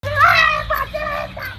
Play, download and share AAAH Pateta original sound button!!!!
aaaah-pateta.mp3